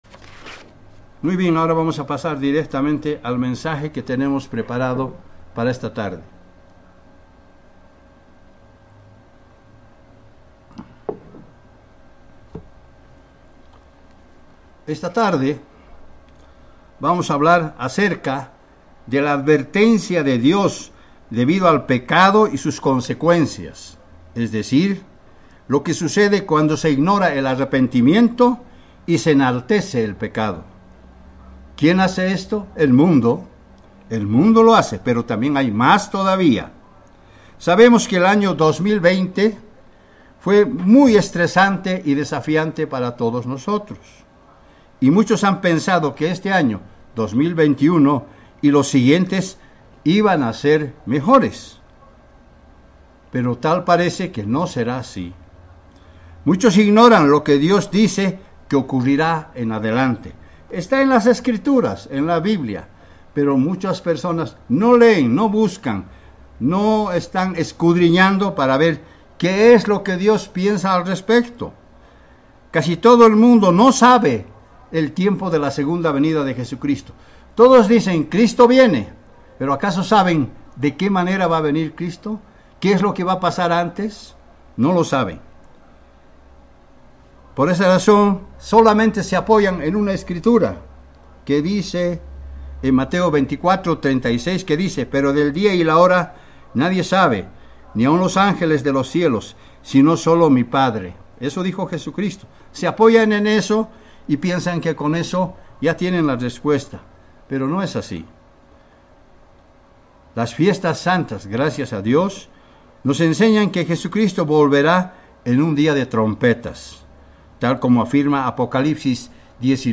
Mensaje entregado el 30 de enero de 2021.